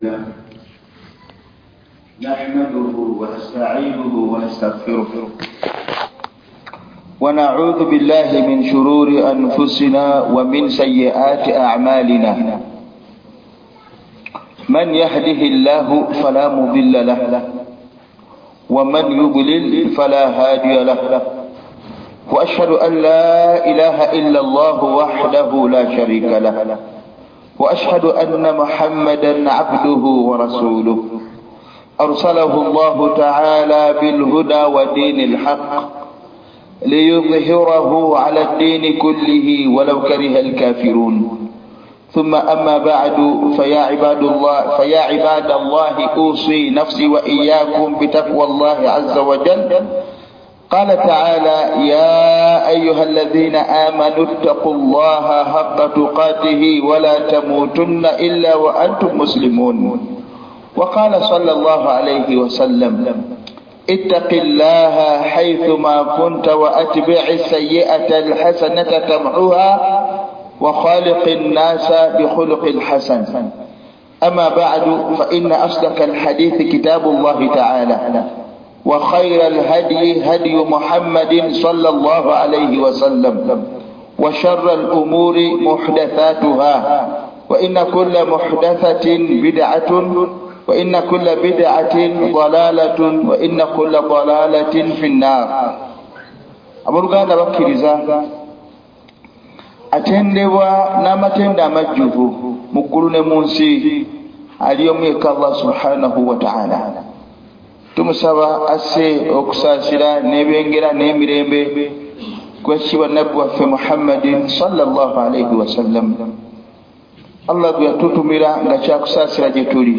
JUMMA KHUTUB